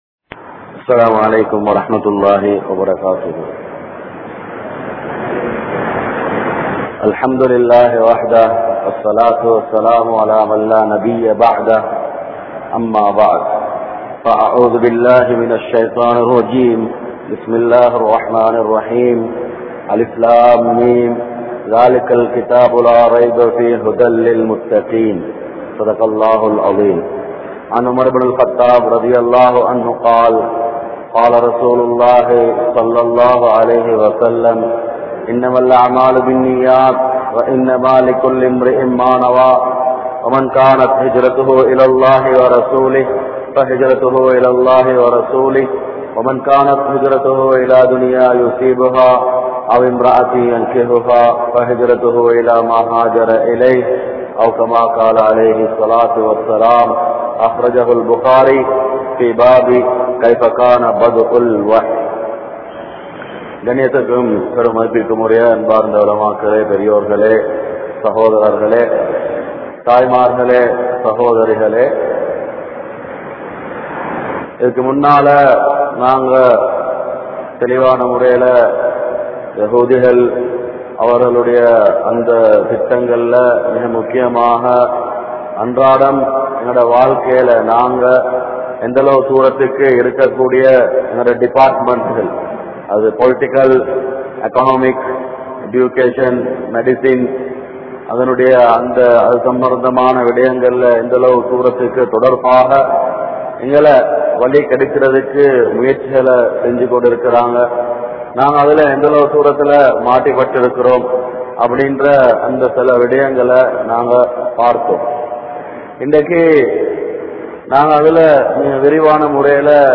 Islathitku Ethiraana Soolchihal(இஸ்லாத்திற்கு எதிரான சூழ்ச்சிகள்) | Audio Bayans | All Ceylon Muslim Youth Community | Addalaichenai
Galle,Gintota, Hussain Jumua Masjith